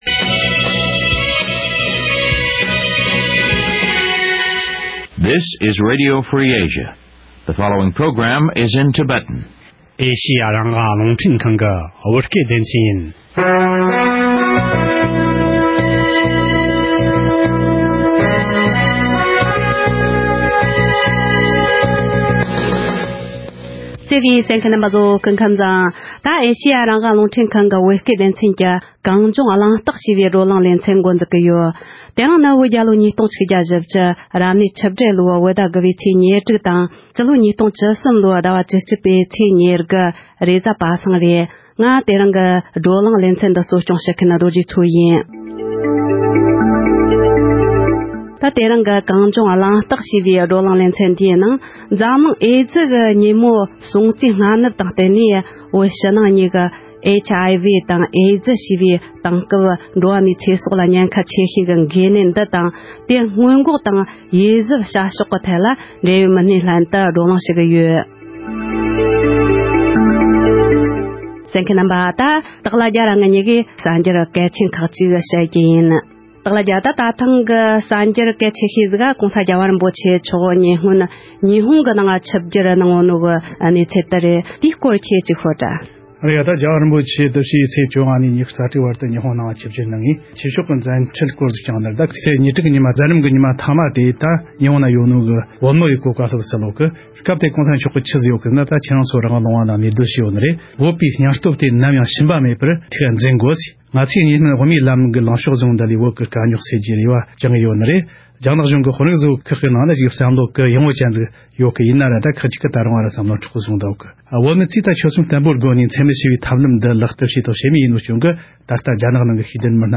༄༅། །དེ་རིང་གི་གཏམ་གླེང་ལེ་ཚན་ནང་། འཛམ་གླིང་ཨེ་ཙེ་ཉིན་མོ་དང་སྟབས་བསྟུན་གྱིས་ཨེ་ཙེ་ནད་གཞི་འདི་བོད་ནང་ཁྱབ་གདལ་ཇི་ཡོད་དང་ནད་གཞི་འདིས་བོད་མི་རིགས་ལ་ཉེན་ཁའི་རང་བཞིན། དེ་བཞིན་ནད་གཞི་འདིའི་སྔོན་འགོག་དང་སློབ་གསོའི་སྐོར་ལ་འབྲེལ་ཡོད་སྨན་པ་དང་ ལྷན་བཀའ་མོལ་ཞུས་པ་ཞིག་གསན་རོགས་གནང་།།